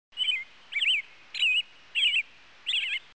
For the timer, I created a Flash application that plays a bird sound at every 15 minutes. By playing a bird sound, the reminder is more pleasant and less annoying.
birds.mp3